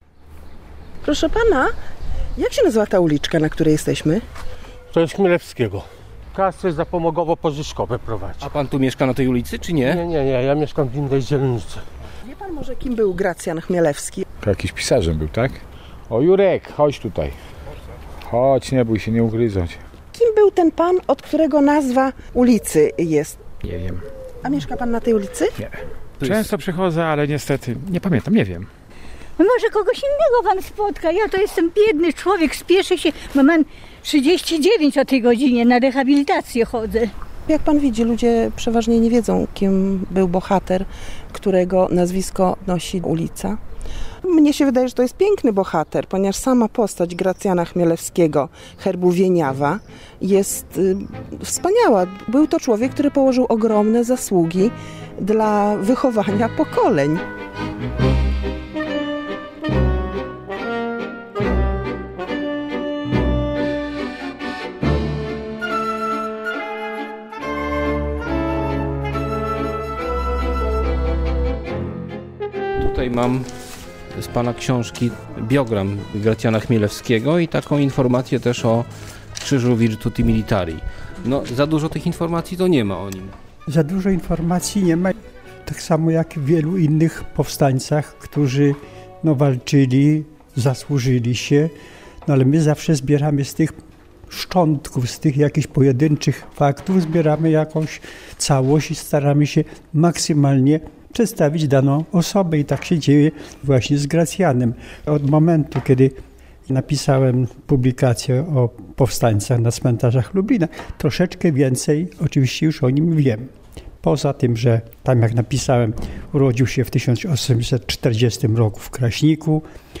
Tagi: reportaż